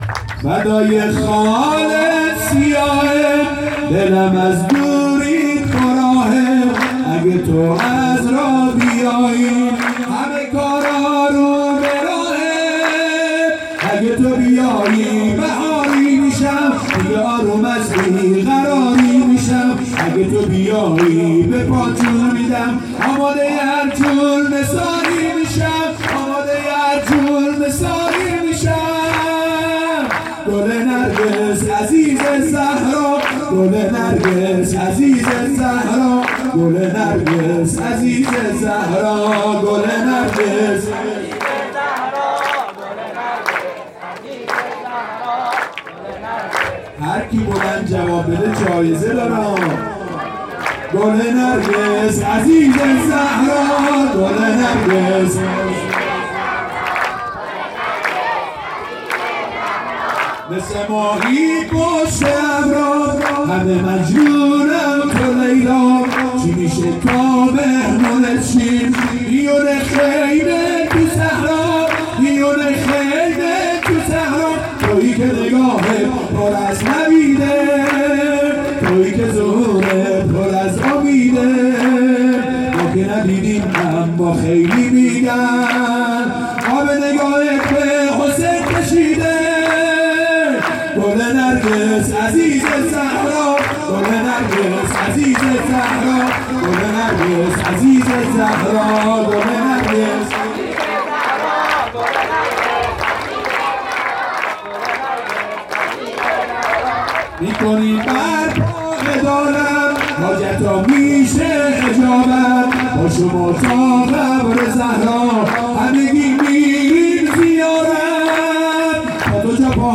مراسم جشن بزرگ نیمه شعبان98هیئت میثاق الحسین علیه السلام سیستان